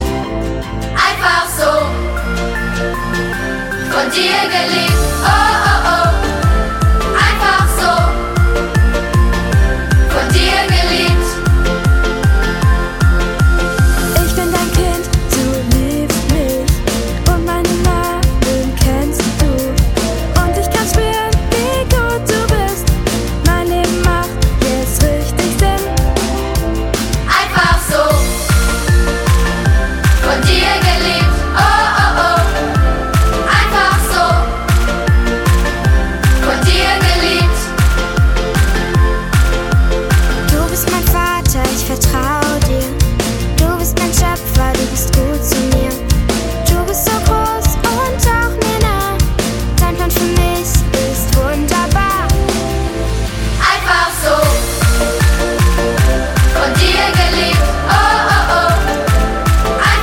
Kinderlieder